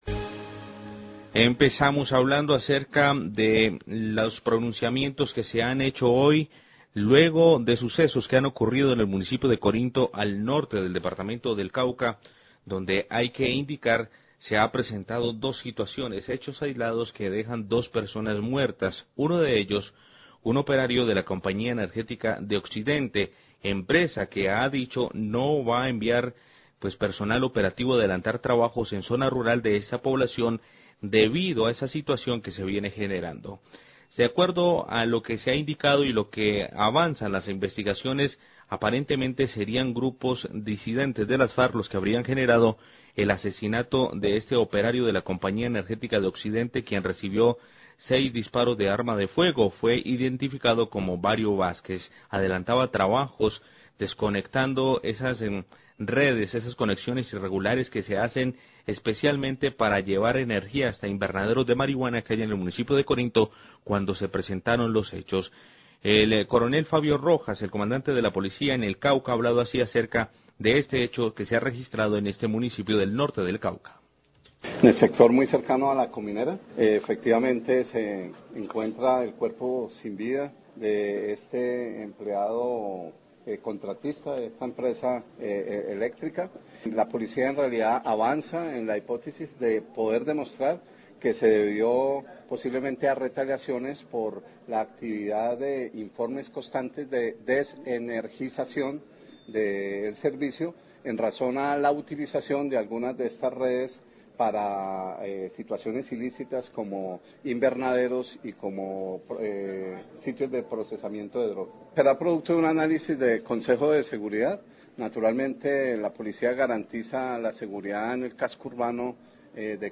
Radio
Declaraciones del Coronel Fabio Rojas, Comandante de la Policía en el Cauca.